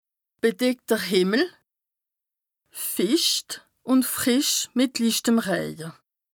Bas Rhin
Ville Prononciation 67
Strasbourg